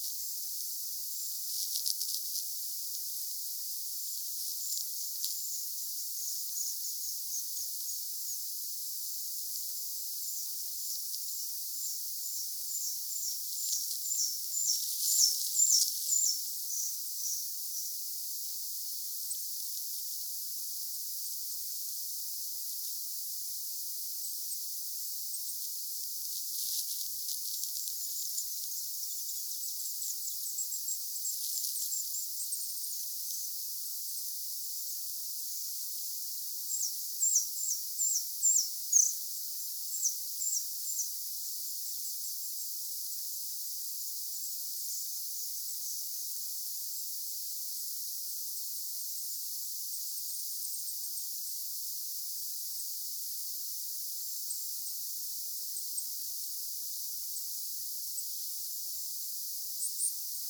Puukiipijälintu matkii idänuunilinnun laulua!
puukiipijälintu matkii hetken idänuunilinnun laulua
Hieman kuuluu hippiäisenkin ääntä.
puukiipijalintu_matkii_idanuunilintua_aantelyssaan_puukiipijalinnun_erikoista_aantelya_hippiainen.mp3